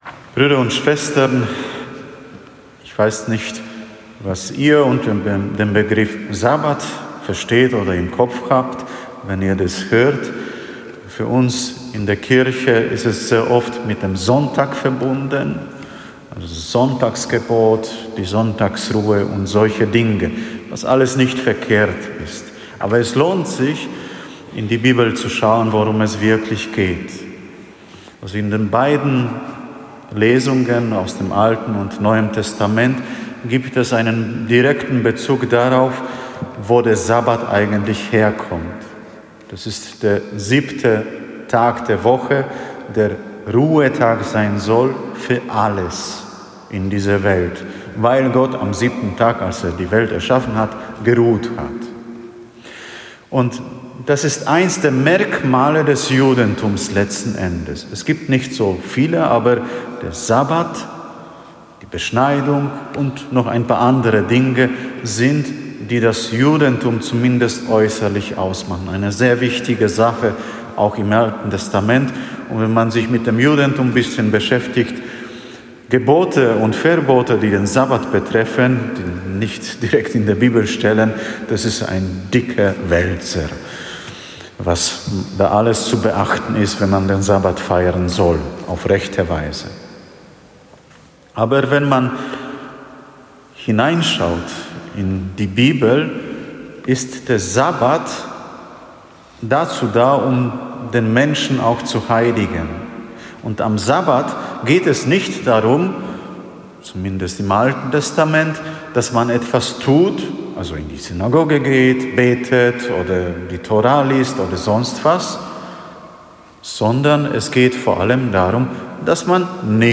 Eine Predigt zum 9. Sonntag im Jahreskreis B